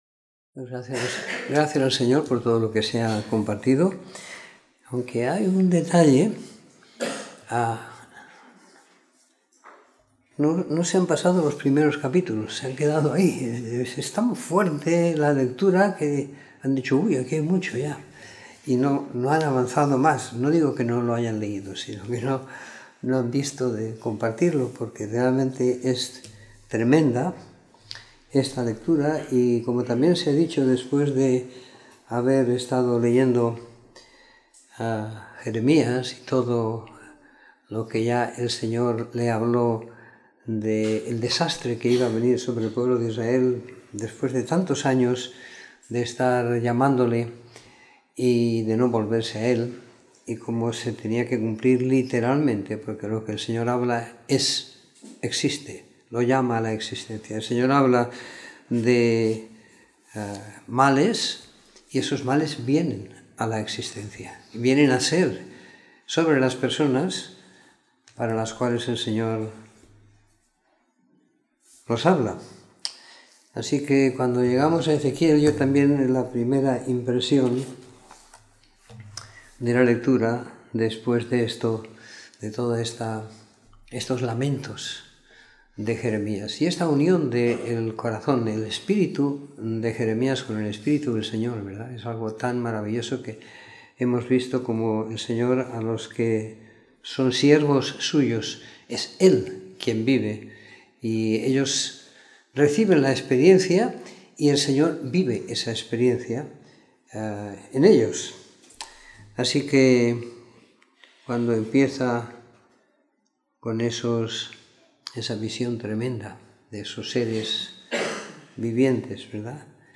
Comentario en Ezequiel 1-32 - 09 de Noviembre de 2018
Comentario en el libro de Ezequiel del capítulo 1 al 32 siguiendo la lectura programada para cada semana del año que tenemos en la congregación en Sant Pere de Ribes.